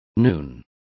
Also find out how mediodias is pronounced correctly.